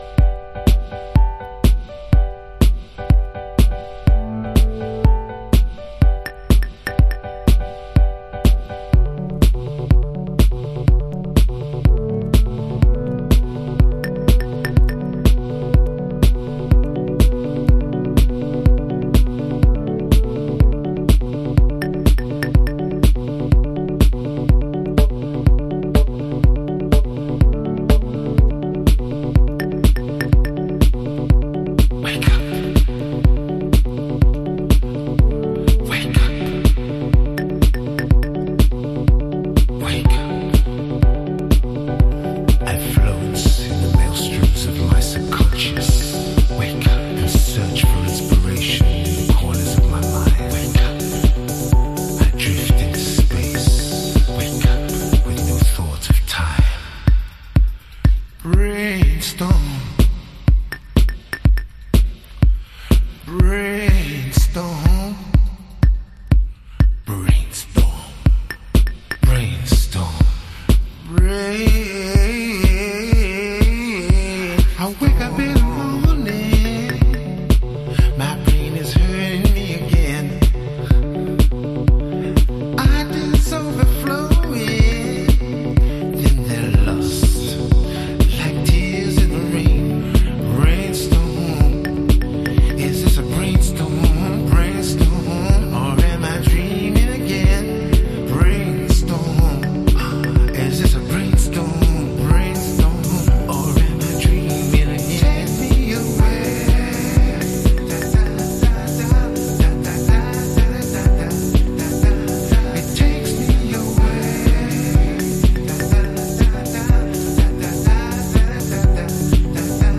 Chicago Oldschool / CDH
Vox Remix